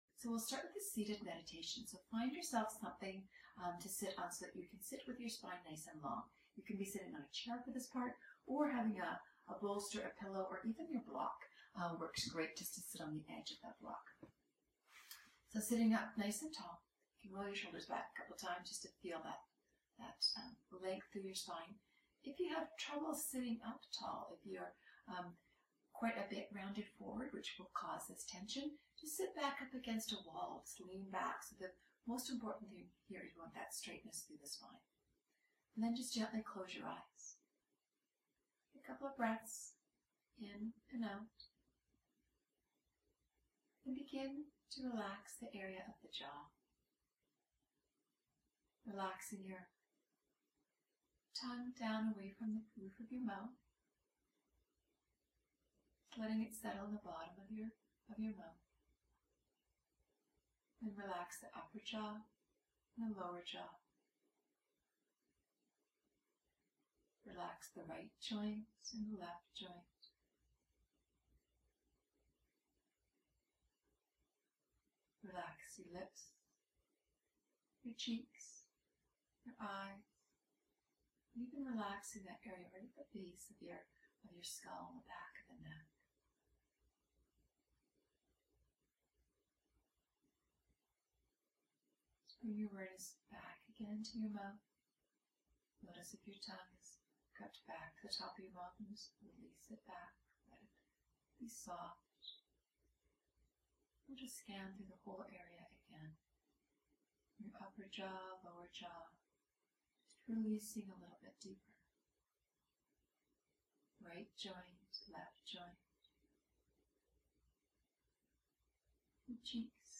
Listen to the meditation starting this series on its own before you go to bed and when you get up in the morning if you are grinding your teeth at night.
yoga+for+tmj+pain_meditation.m4a